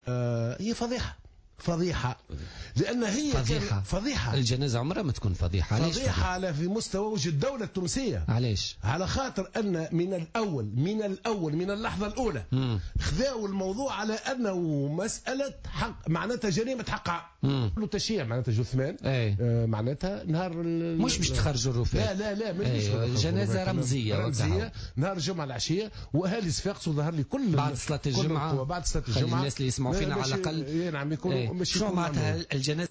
و أضاف معطر ضيف برنامج "بوليتيكا" اليوم الثلاثاء أن الجنازة التي أقيمت للمرحوم كانت بمثابة "الفضيحة"، وفق تعبيره.